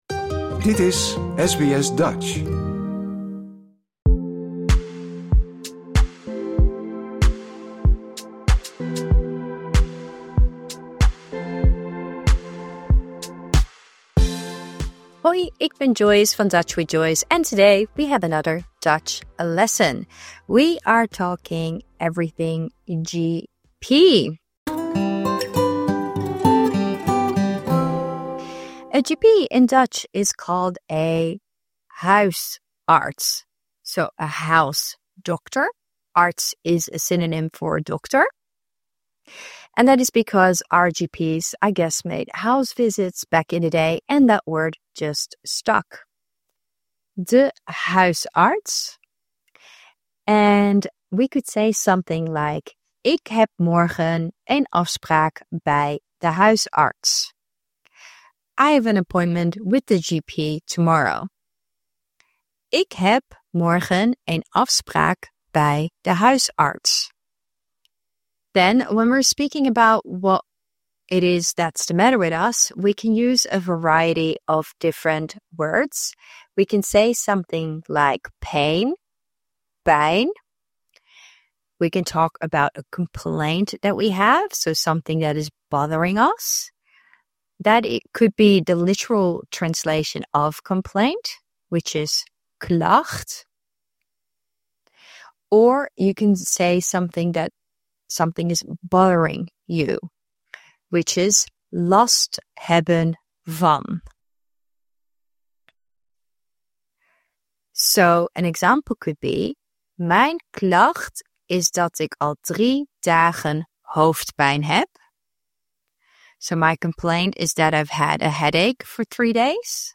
Words and sentences from lesson 78: the GP English Dutch general practitioner / GP (de) huisarts I have an appointment with the GP tomorrow.